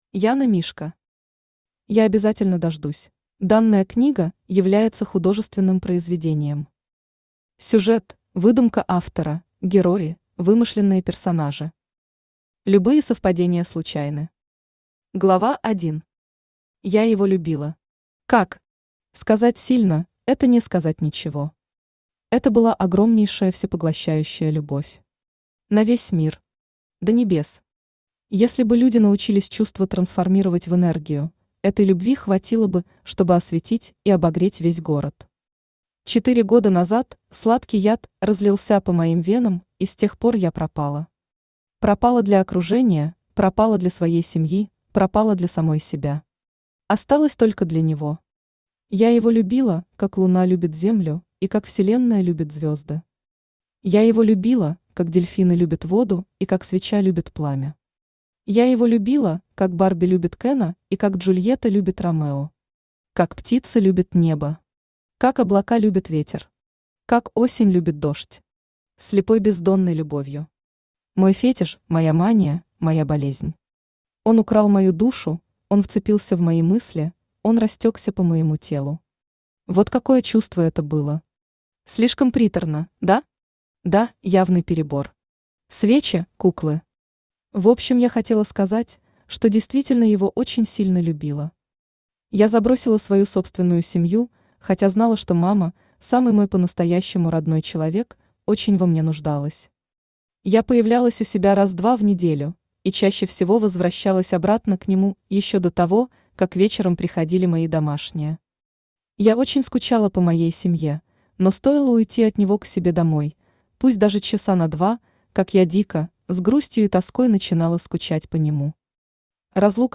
Aудиокнига Я обязательно дождусь Автор Яна Мишка Читает аудиокнигу Авточтец ЛитРес.